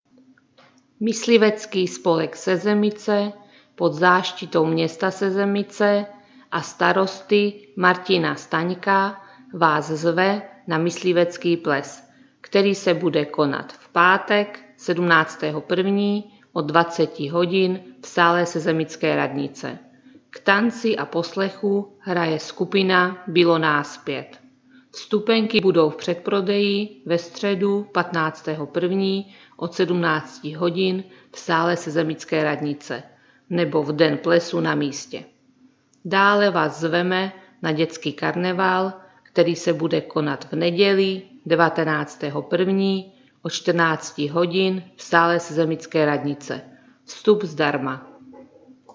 Hlášení městského rozhlasu 10. a 11.01. – 13. a 14.01.2025